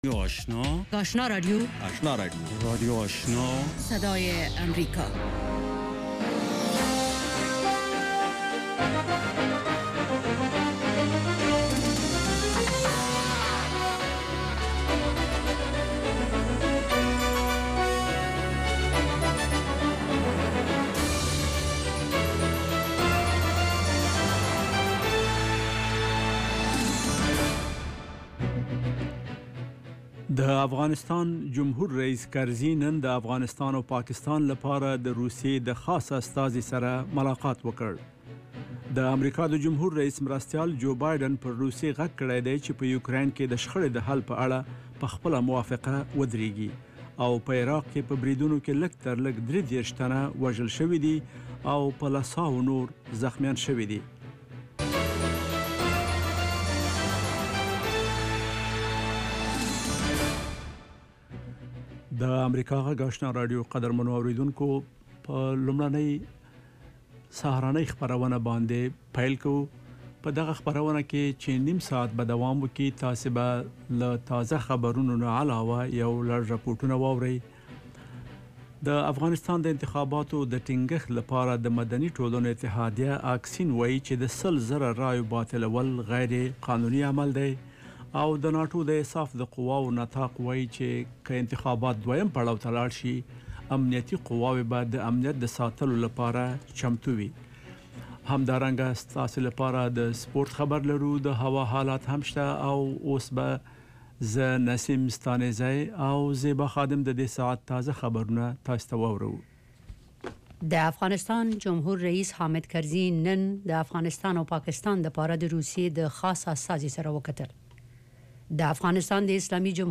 ماښامنۍ خبري خپرونه
یو ساعته پروگرام: د ورځې د مهمو سیاسي، اجتماعي او نورو مسایلو په اړه له افغان چارواکو او کارپوهانو سره خبرې کیږي. د اوریدونکو پوښتنو ته ځوابونه ویل کیږي. ددغه پروگرام په لومړیو ١٠ دقیقو کې د افغانستان او نړۍ وروستي خبرونه اورئ.